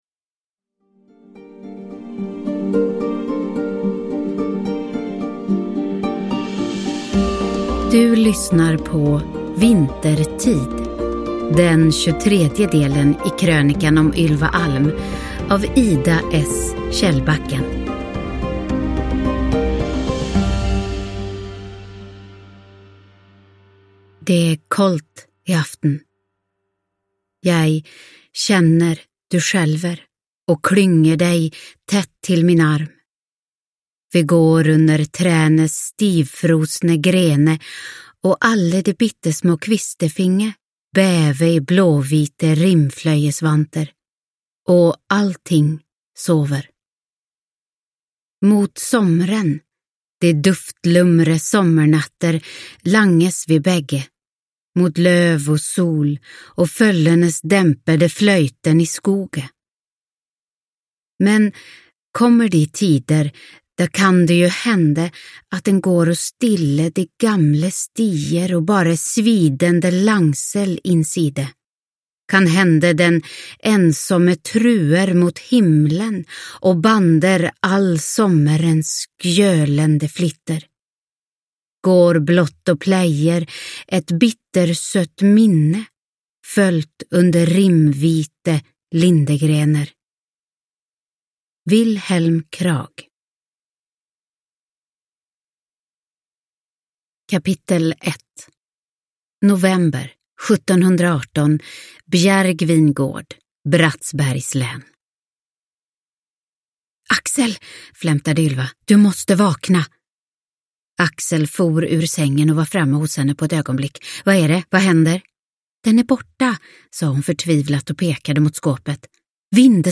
Vintertid – Ljudbok